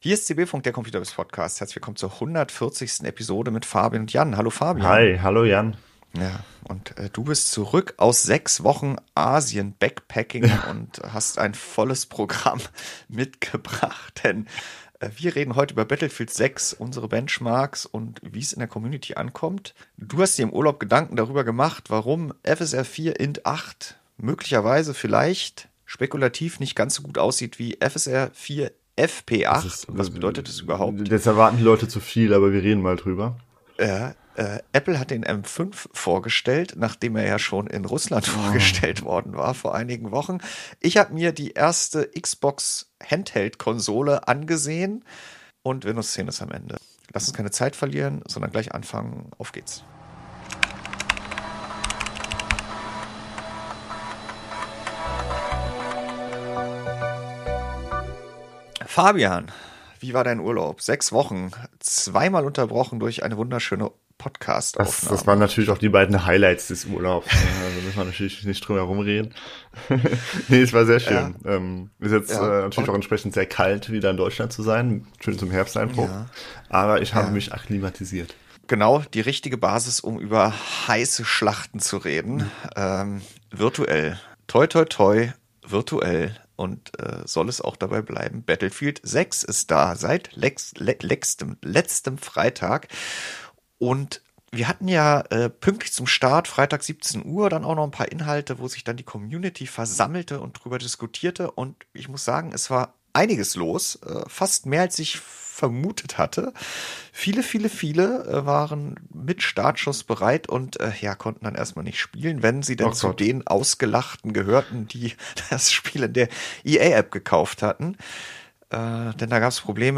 Die Redaktion von ComputerBase diskutiert im CB-Funk ganz ohne Gebrüll aktuelle Themen und gewährt Einblicke hinter die Kulissen: Warum hat die Redaktion wie berichtet oder getestet, was steht an, oder wo klemmt es?